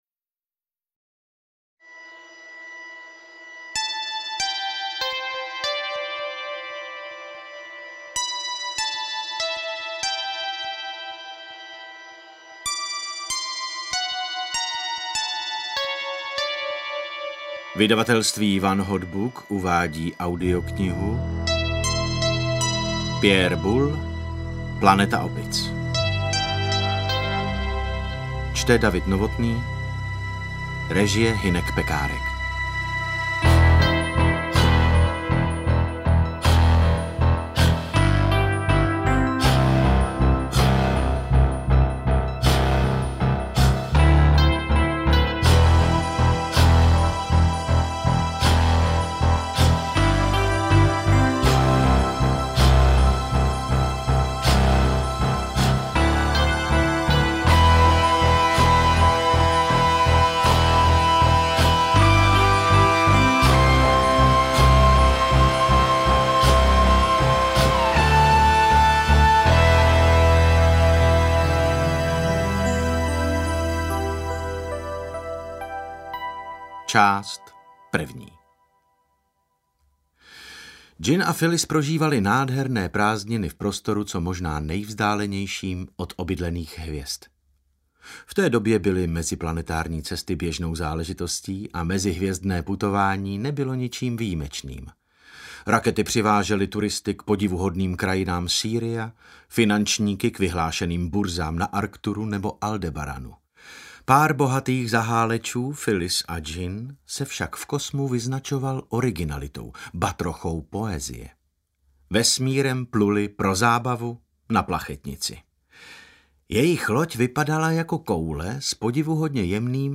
Interpret:  David Novotný
AudioKniha ke stažení, 38 x mp3, délka 5 hod. 52 min., velikost 311,0 MB, česky